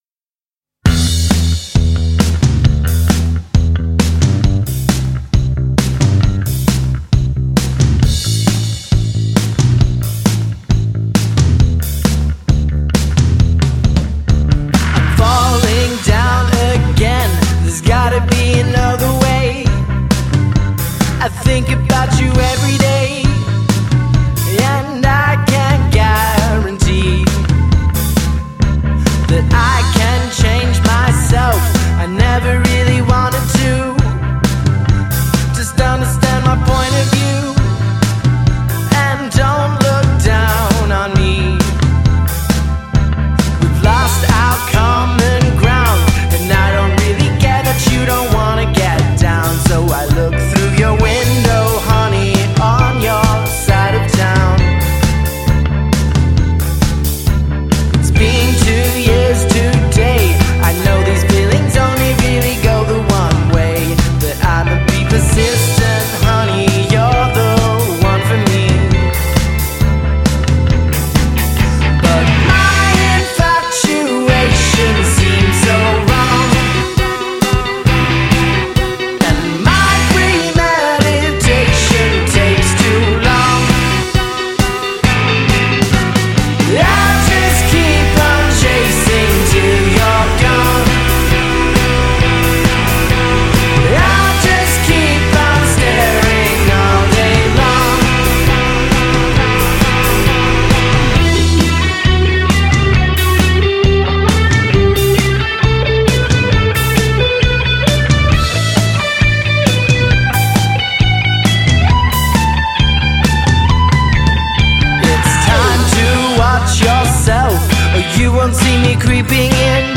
It’s catchy, fun, vibrant, and overwhelmingly energetic.